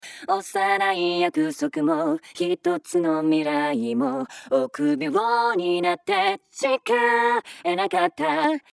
メインボーカルのユニゾン（コーラス）を簡単に作成
▼オクターブ下げただけのユニゾン
Ex3_OctVocal_Before.mp3